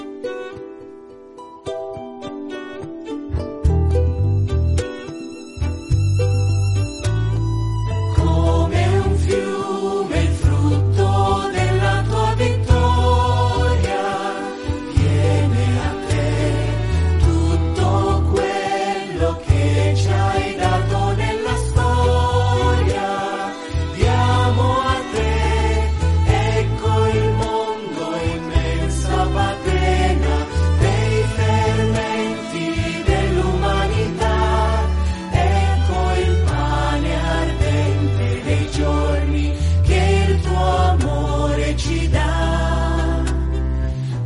Il canto di avvento prepara, come in un cammino, l'avvicinarsi al Natale: ecco allora che i tempi musicali sono quasi tutti in 3/4 o 6/8, come a mimare quel passo che ci accompagna verso il mistero: canti di meditazione e di incontro con la Parola che salva: non ci sarà più il Gloria, ma daremo spazio al canto della misericordia di Dio, invocando Kyrie, eleison!